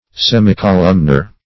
Search Result for " semicolumnar" : The Collaborative International Dictionary of English v.0.48: Semicolumnar \Sem`i*co*lum"nar\, a. Like a semicolumn; flat on one side and round on the other; imperfectly columnar.